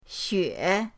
xue3.mp3